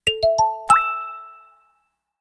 02_Water_Drop.ogg